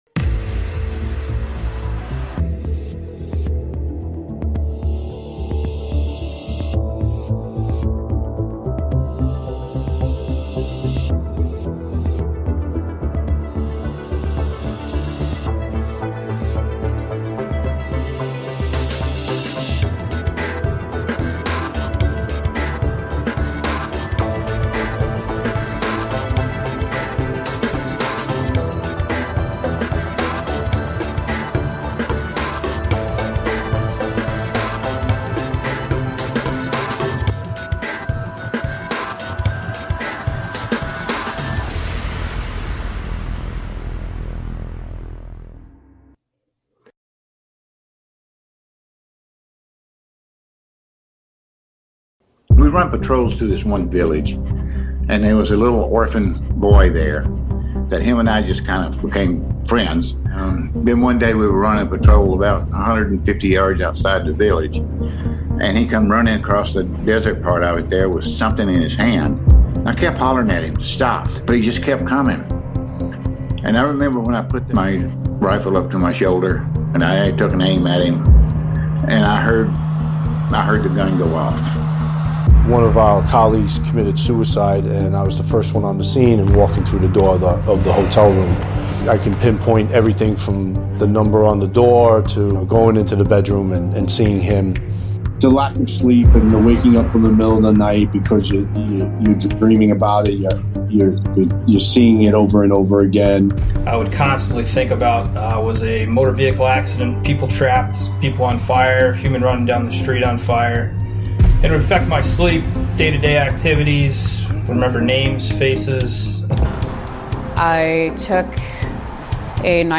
Talk Show Episode
Join us as veterans, firefighters, paramedics, police officers, and dispatchers openly share their personal journeys.